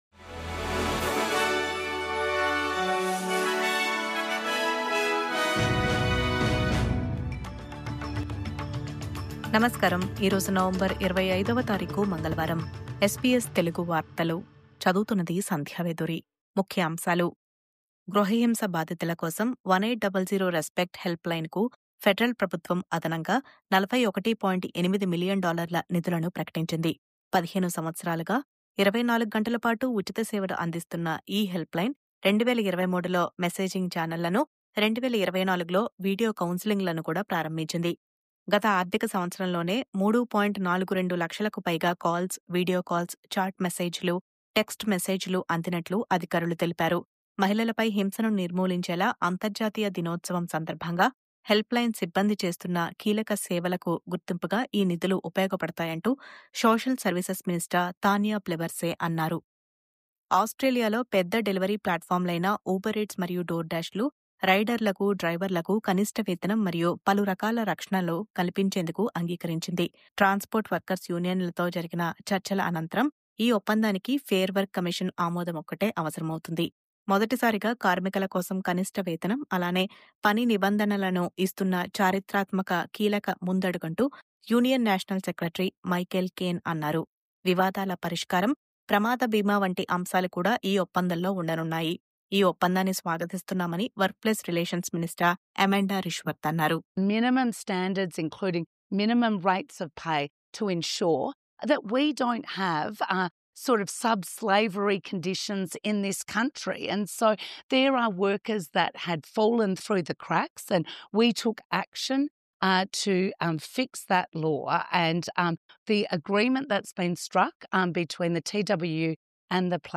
News update: Uber, DoorDash డెలివరీ కార్మికులకు వేతన పెంపు, ప్రమాద బీమా మరియు ఇతర నిబంధనలపై ఒప్పందం..